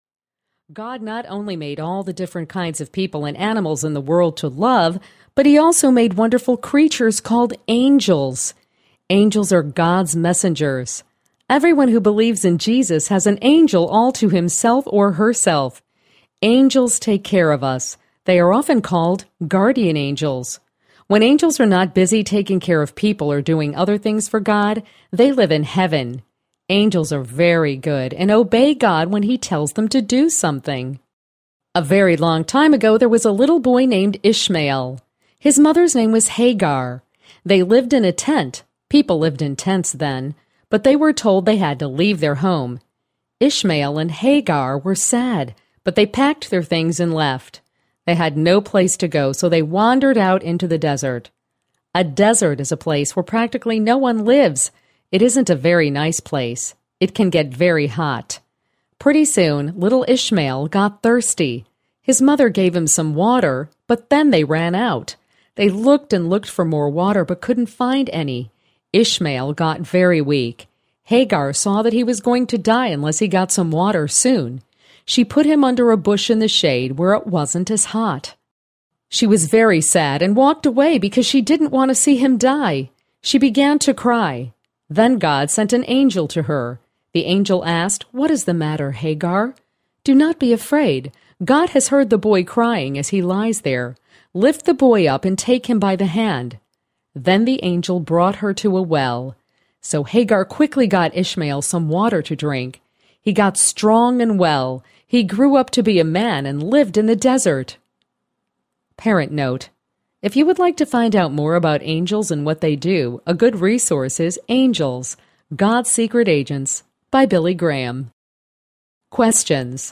Step Into the Bible Audiobook
3.65 Hrs. – Unabridged